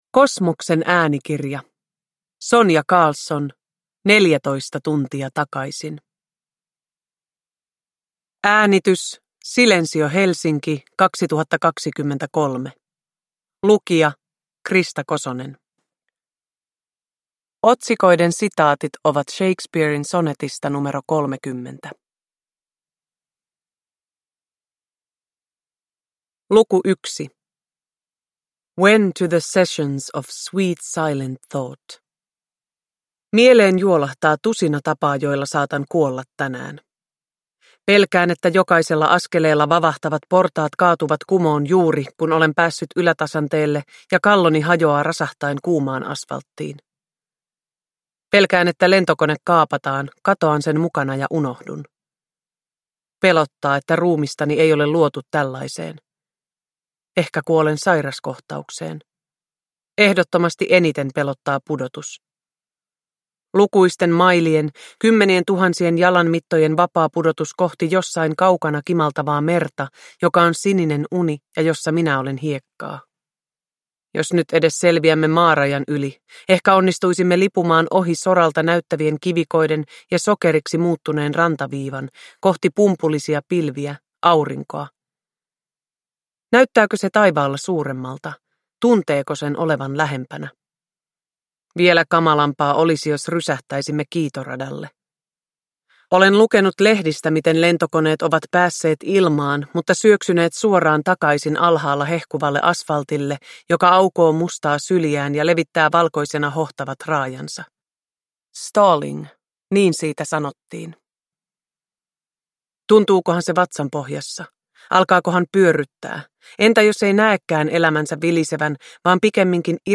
14 tuntia takaisin – Ljudbok – Laddas ner
Uppläsare: Krista Kosonen